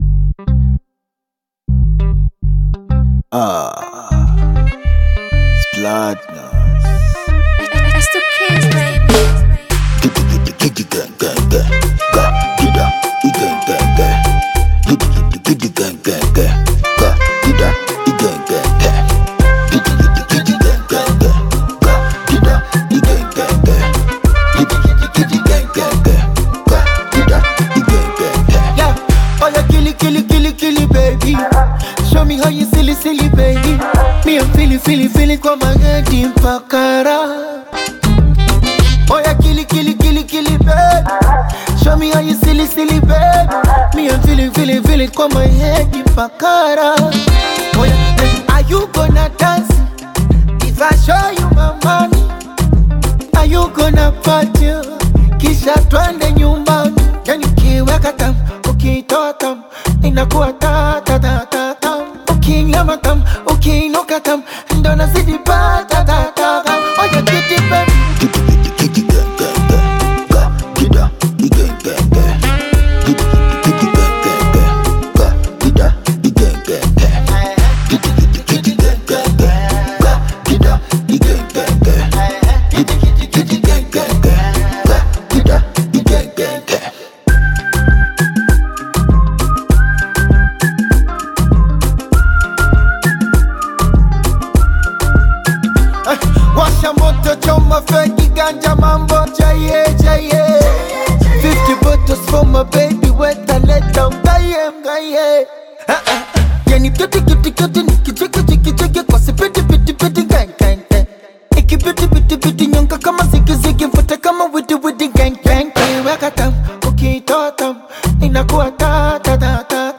A classic Afrobeats record
blends it with the Western latino sound.